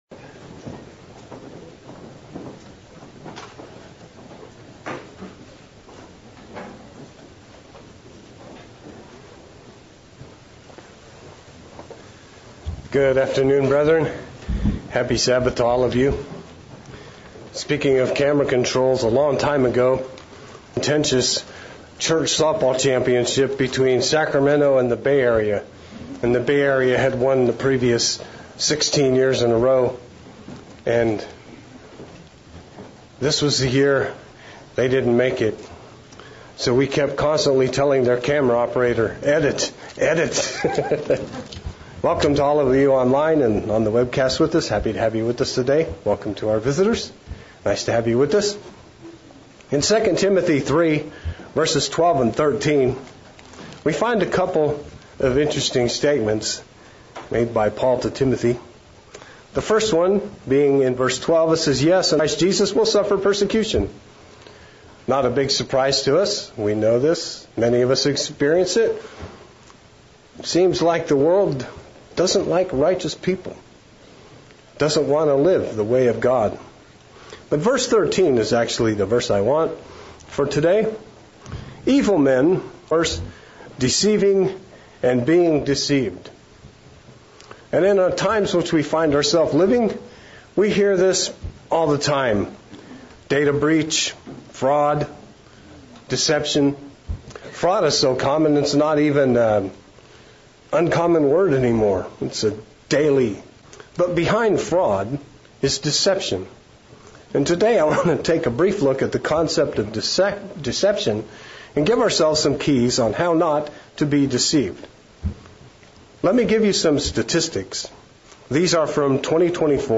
Sermonette looking at deception in the world and in the Bible and how easy it can be to fall for deception.